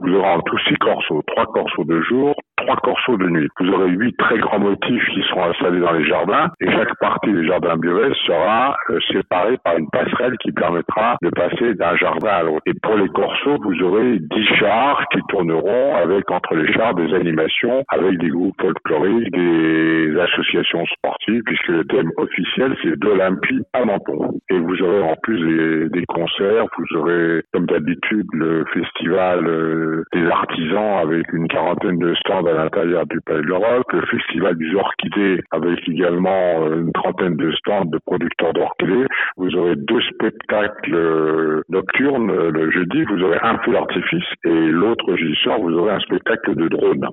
Présentation de cette 90ème édition avec le maire de la commune Yves Juhel. Nous l'avons joint par téléphone